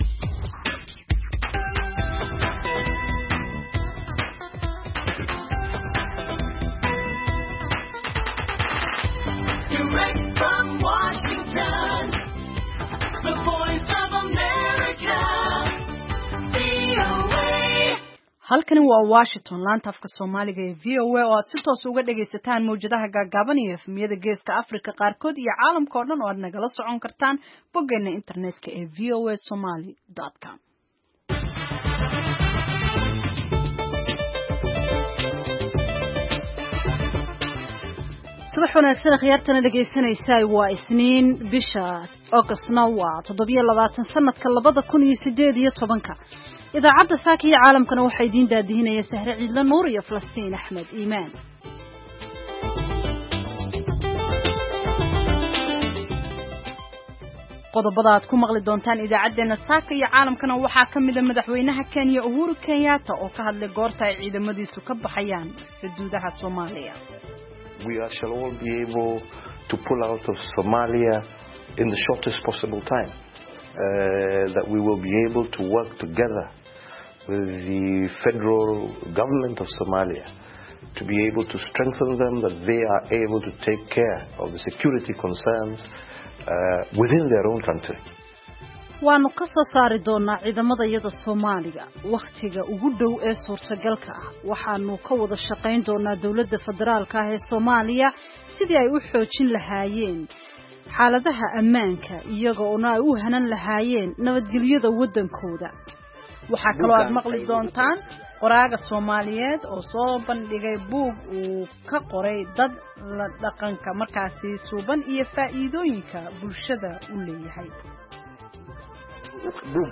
Idaacadda Subaxnimo ee Saaka iyo Caalamka waxaad ku maqashaa wararkii habeenimadii xalay ka dhacay Soomaaliya iyo waliba caalamka, barnaamijyo, wareysi xiiso leh, ciyaaraha, dhanbaallada dhagaystayaasha iyo waliba wargeysyada caalamku waxay saaka ku waabariisteen.